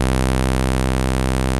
RESO SYNTH 2.wav